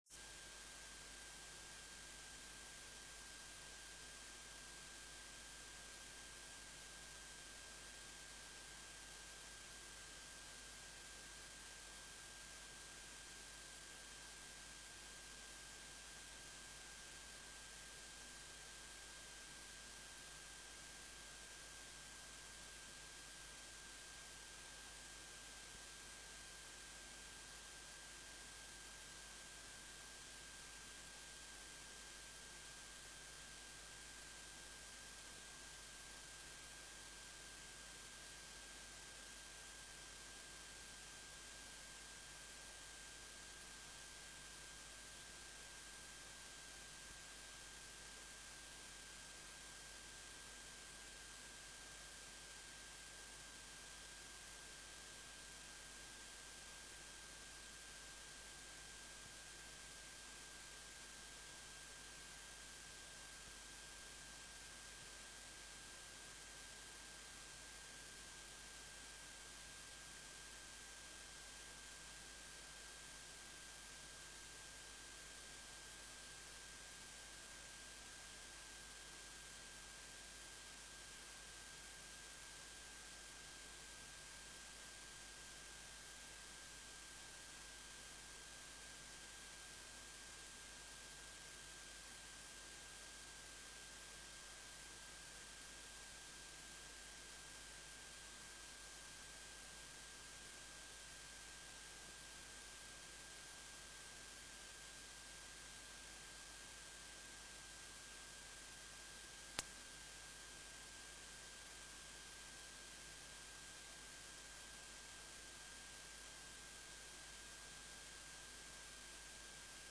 beeldvormende raad 12 september 2013 19:30:00, Gemeente Doetinchem
Download de volledige audio van deze vergadering